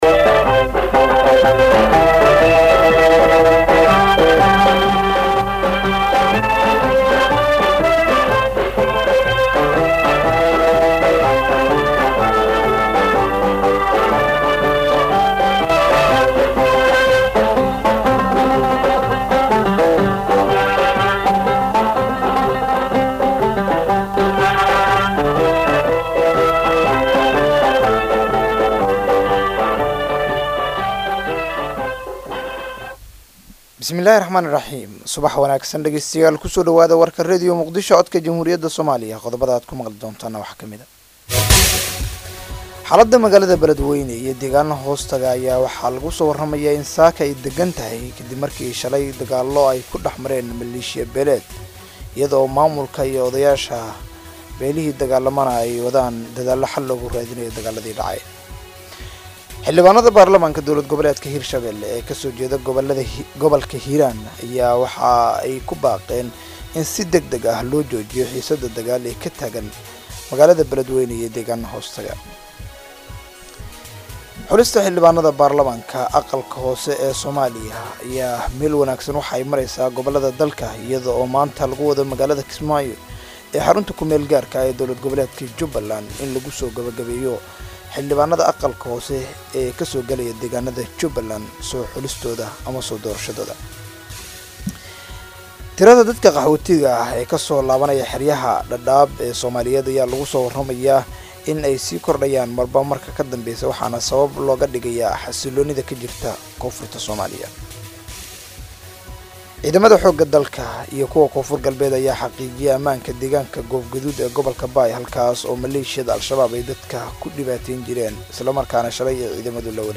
Dhageyso warka subax ee Radio Muqdisho